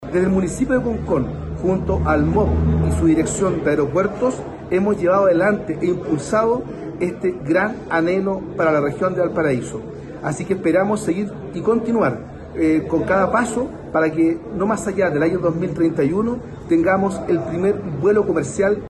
El alcalde de Concón, Freddy Ramírez, celebró la aprobación del documento e indicó que esperan “no más allá del año 2031 tener el primer vuelo comercial en el Aeropuerto de Concón”.